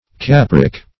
Capric \Cap"ric\, a. [L. caper goat.] (Chem.)